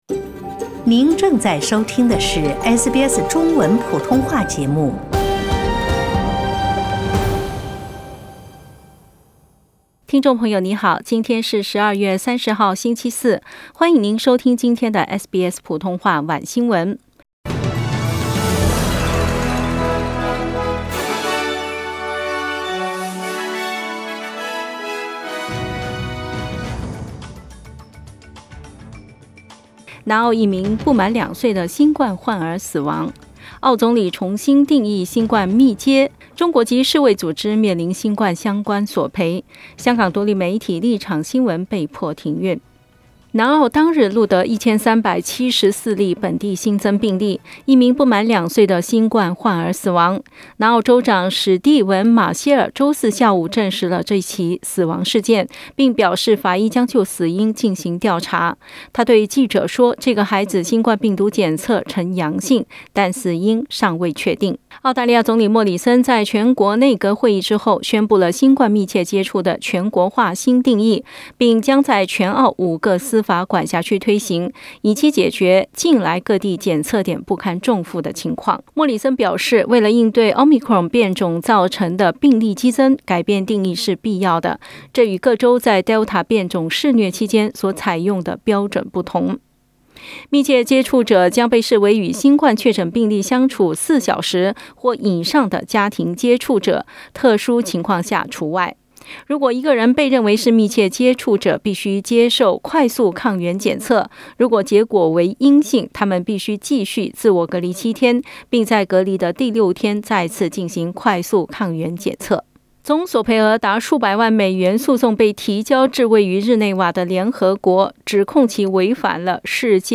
SBS晚新闻（2021年12月30日）
SBS Mandarin evening news Source: Getty Images